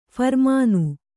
♪ pharmānu